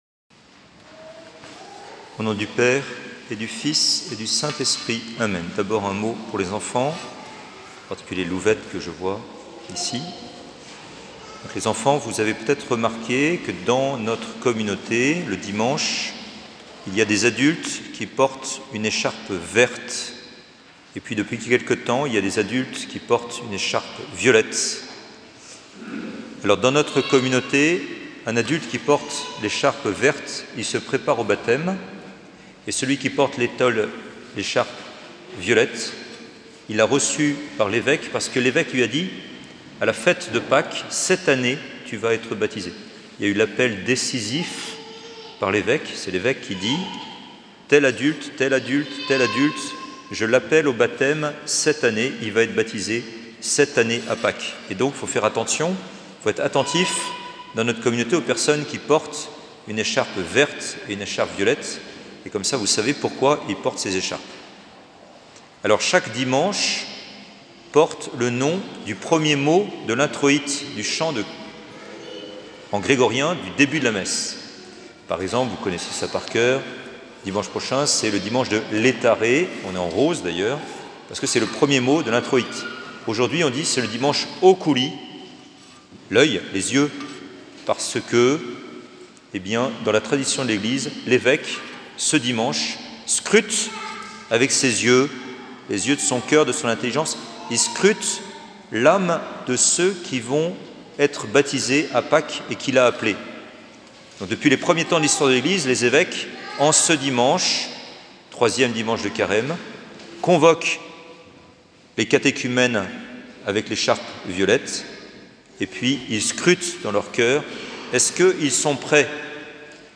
Église catholique Saint-Georges à Lyon
Homélies du dimanche